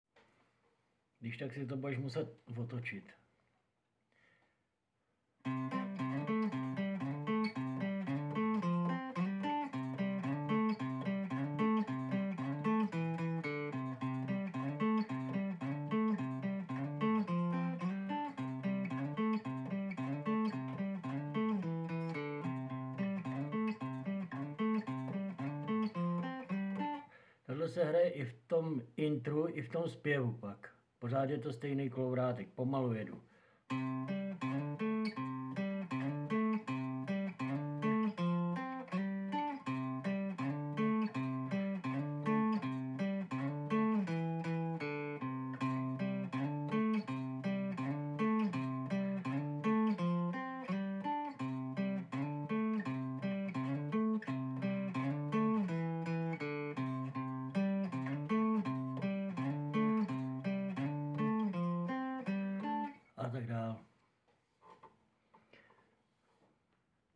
bludicka_basa.mp3